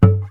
mute-03.wav